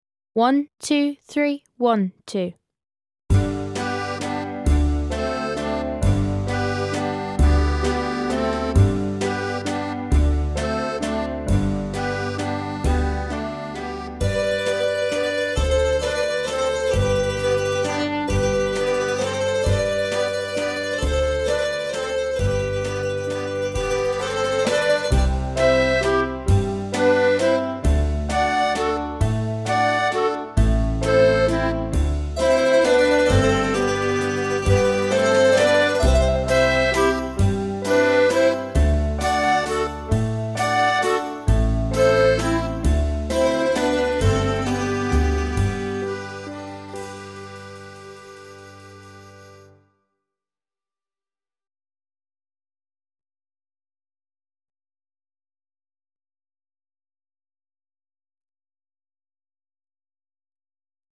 60. Greek Wedding (Backing Track)